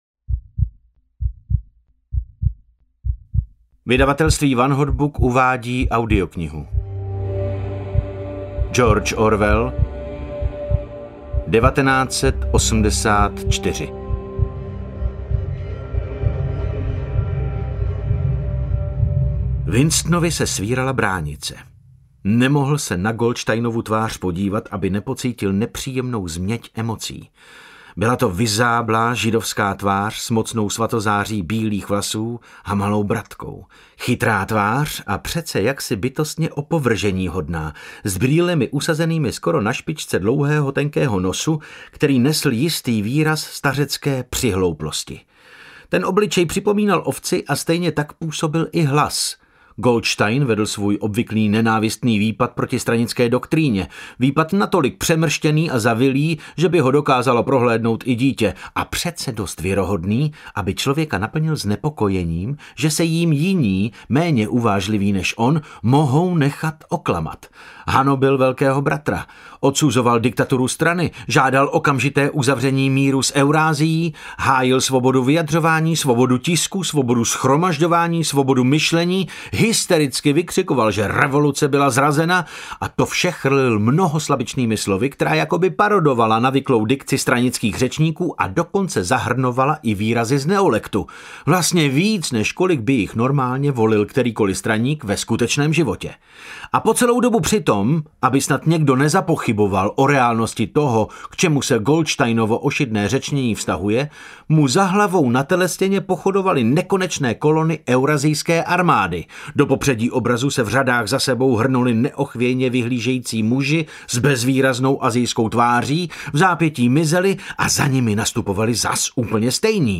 1984 audiokniha
Ukázka z knihy